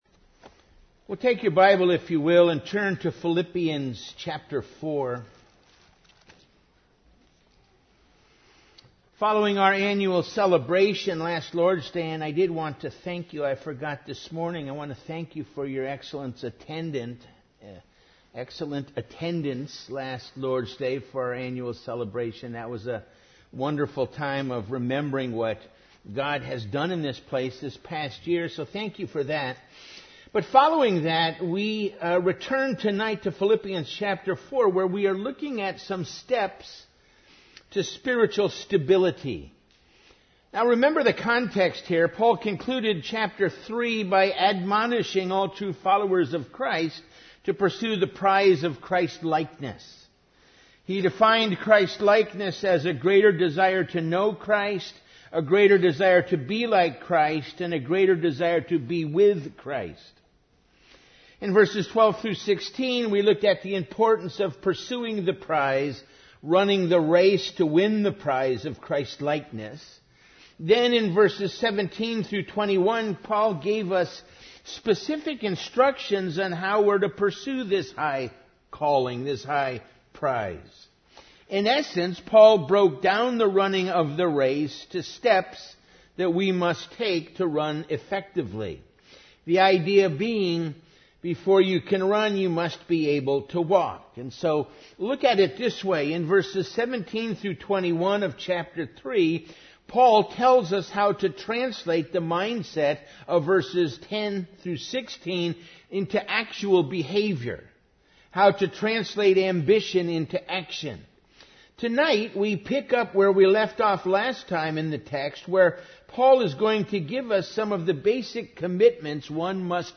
Evening Worship